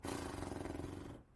fartahhaha
fart_o8NSOwF.mp3